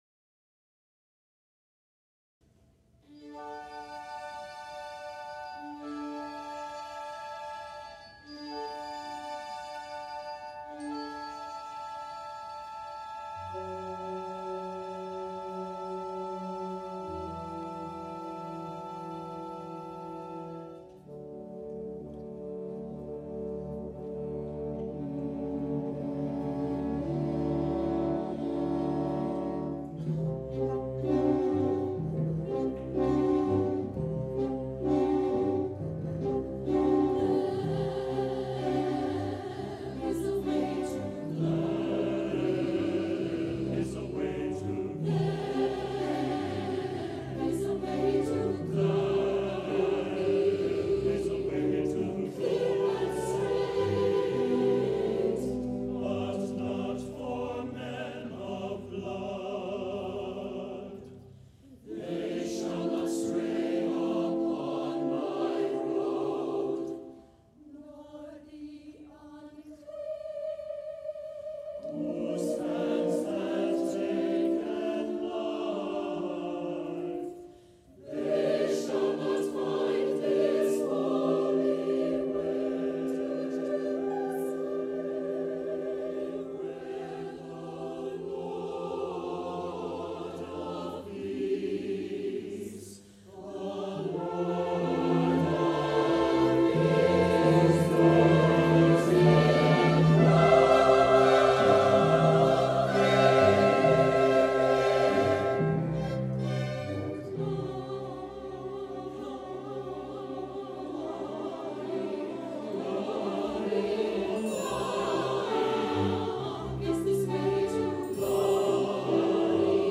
for SATB Chorus and Chamber Orchestra (2004-07)
There is a Way to Glory is a triumphant march to glory, as expressed by the lyrics:
The music is placed in a 2/2 marching meter, and the oscillating pitches on the word "glory" intend to reflect a trembling with ecstasy on the journey to heaven.